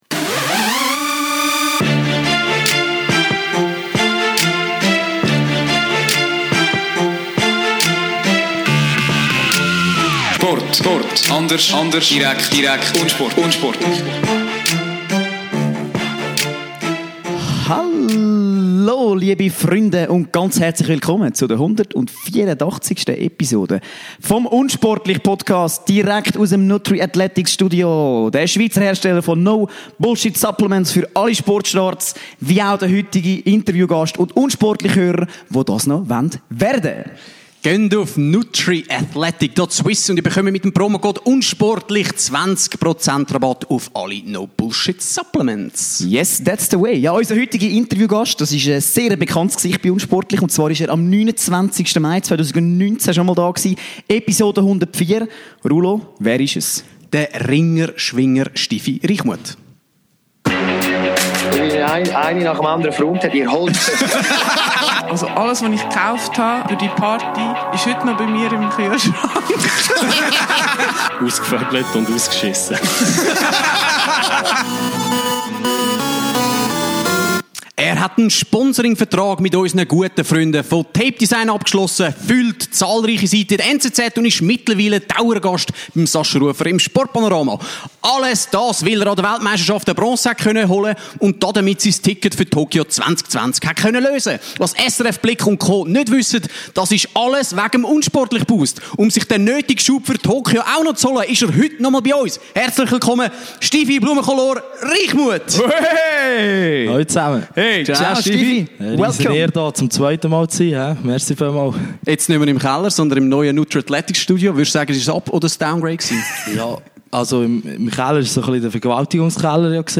Im zweiten Interview verlangen wir daher zuerst einmal ein grosses Dankeschön!